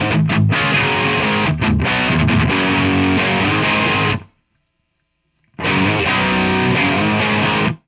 For all examples the guitar used is an SG with a Burstbucker 2 pickup in bridge position.
All mics were placed directly in front of the speakers roughly focused between the center cone and the outer edge at a 45deg angle.
No settings were changed on the amp or guitar during the recordings and no processing or eq was applied to the tracks.
Audix I5 street price $100